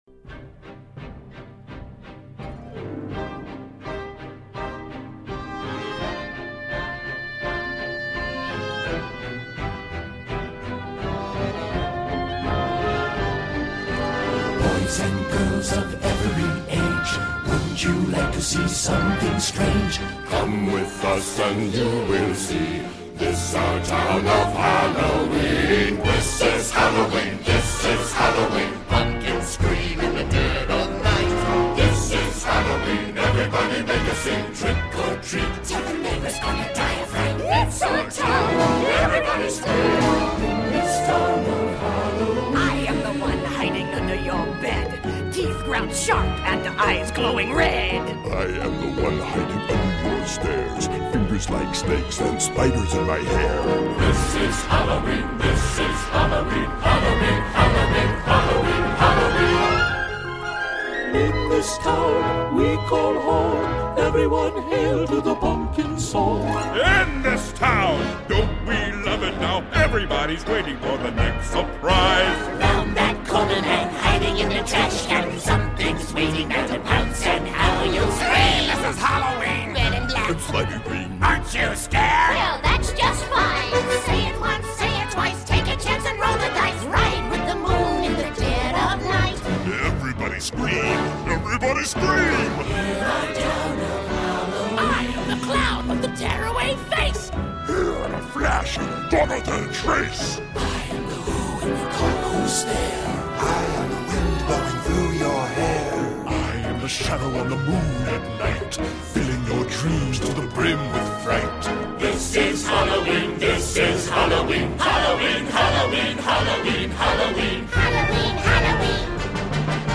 دانلود آهنگ هالووین خارجی با کلام